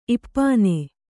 ♪ ippāne